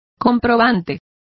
Complete with pronunciation of the translation of vouchers.